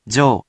We have our computer friend, QUIZBO™, here to read each of the hiragana aloud to you.
#2.) Which hiragana youon do you hear? Hint: 【jyo】
In romaji, 「じょ」 is transliterated as 「jyo」which sounds like the name「Joe」